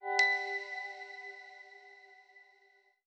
SFX_Menu_Confirmation_01.wav